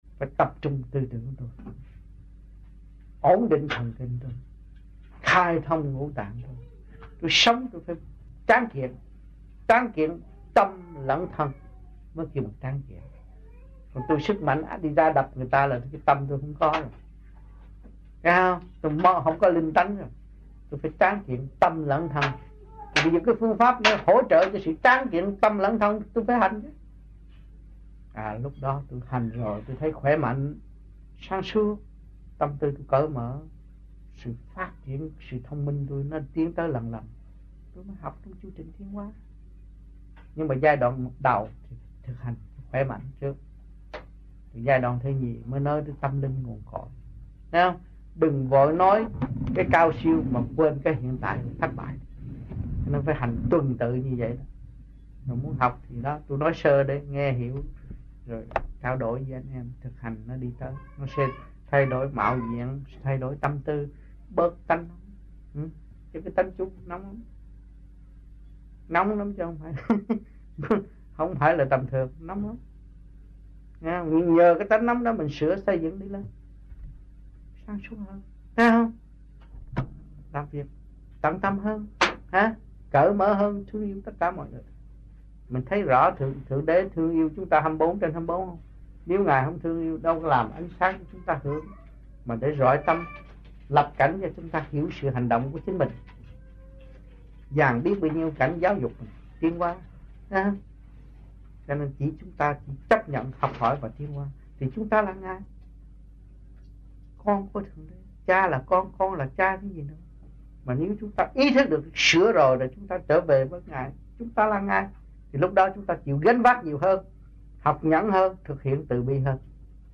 1980-11-20 - AMPHION - THUYẾT PHÁP 02